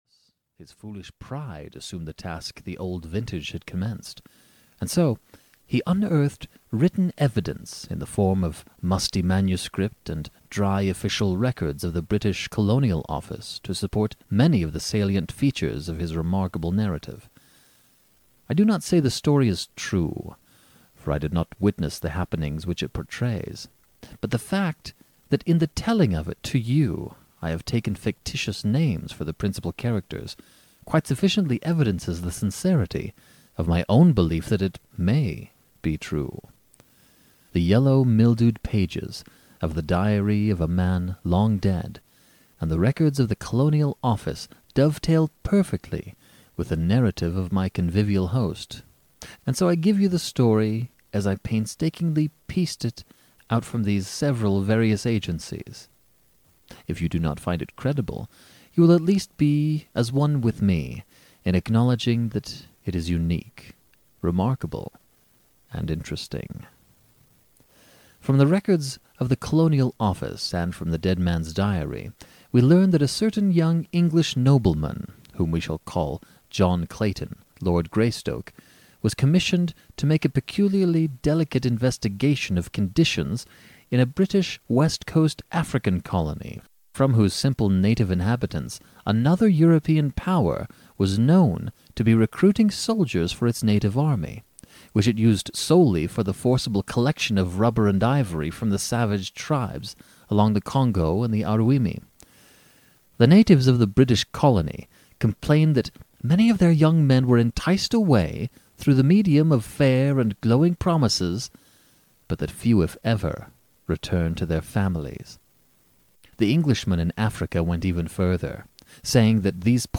audiokniha
Ukázka z knihy